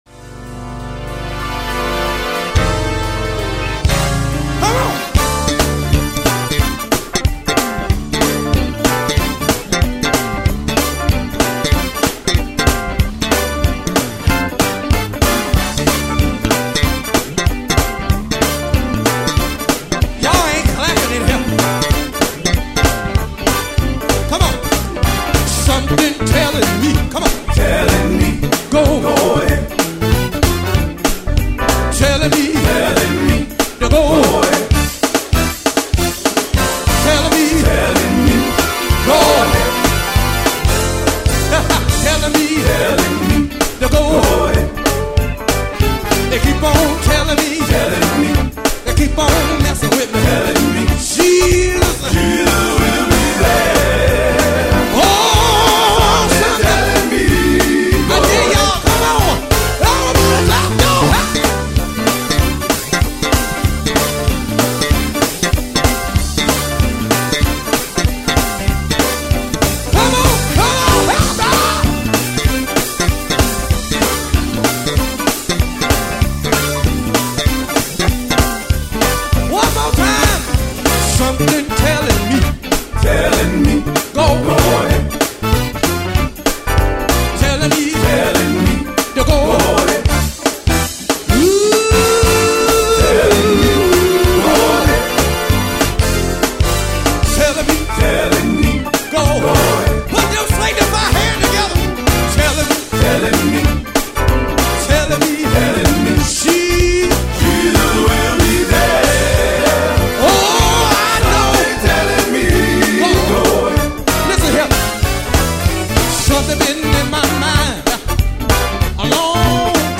gospel quartet